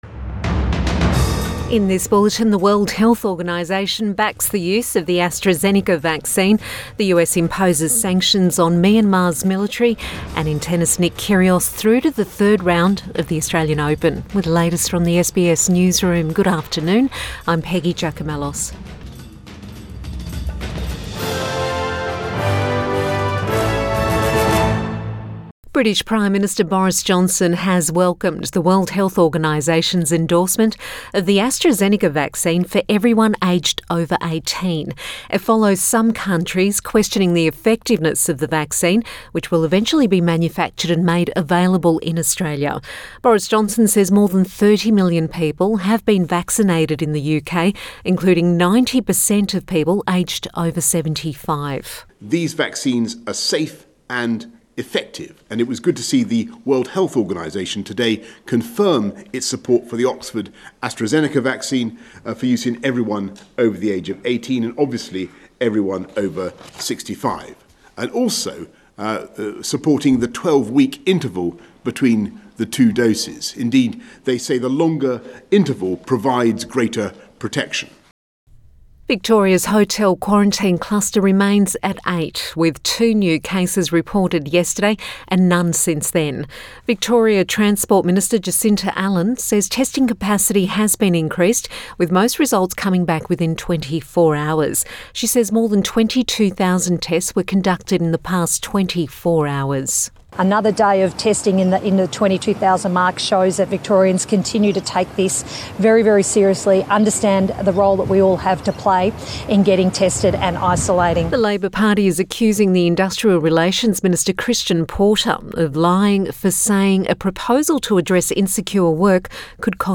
Midday bulletin 11 February 2021